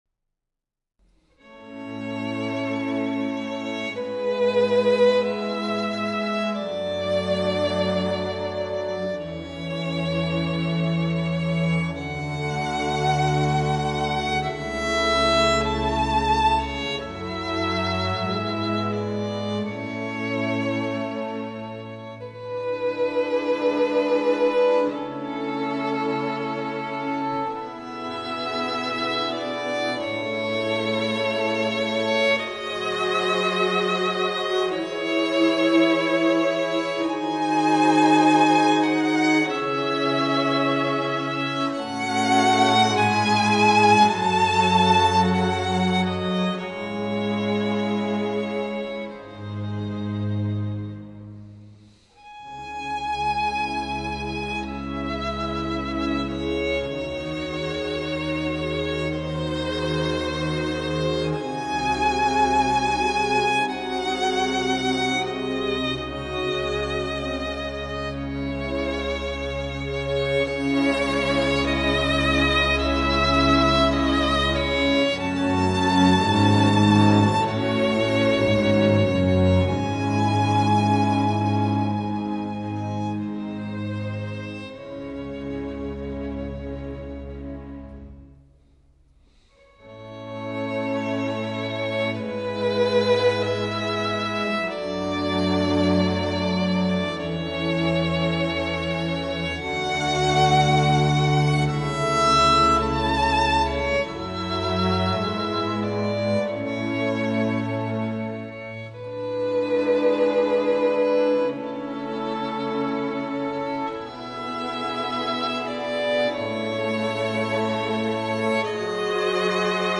per violino e orchestra
violino solista e direttore